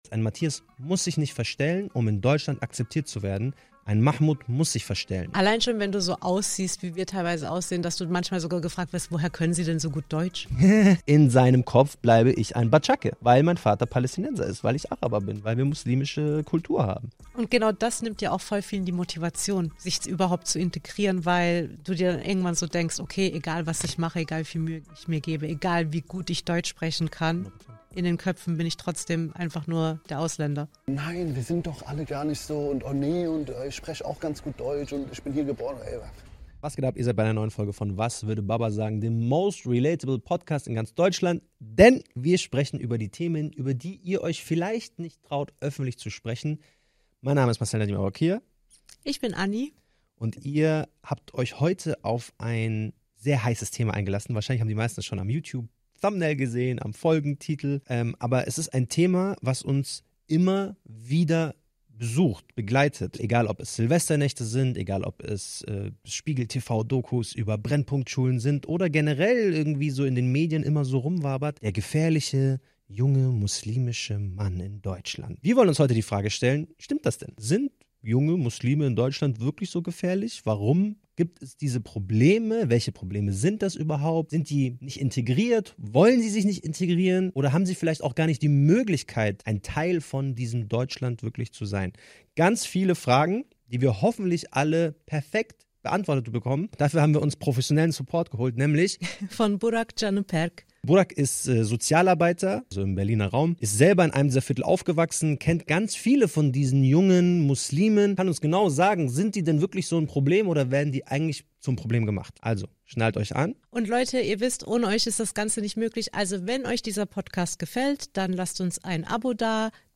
Gemeinsam diskutieren wir über Lösungen für eine bessere Integration und darüber, was die Jugendlichen wirklich brauchen.